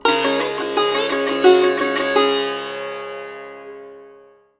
SITAR GRV 03.wav